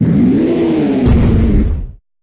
Cri de Boumata dans Pokémon Soleil et Lune.